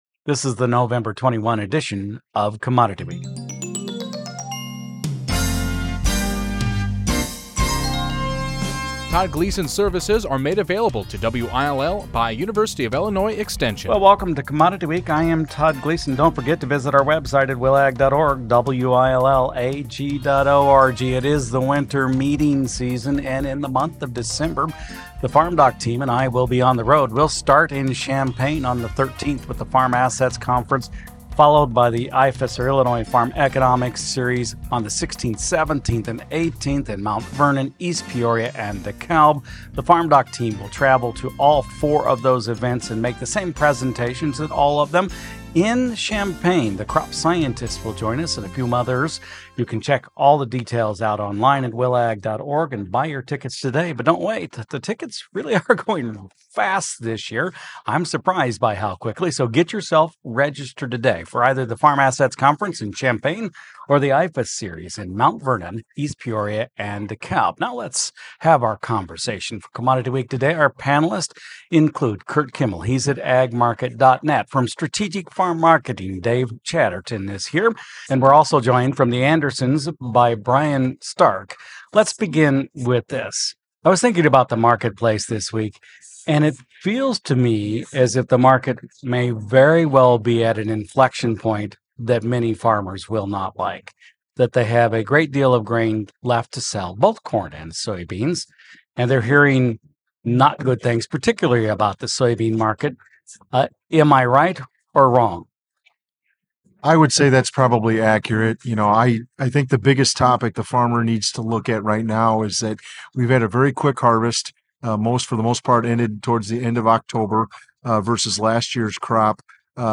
Nov 21 | Closing Market Report